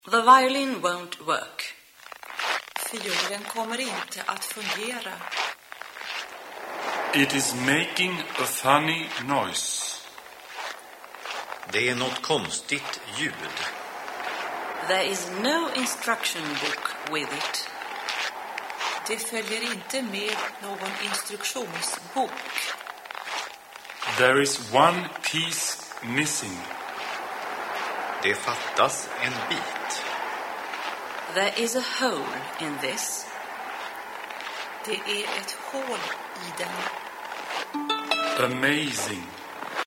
The Jon Rose Web - Radio Violin - Swedish for Violinists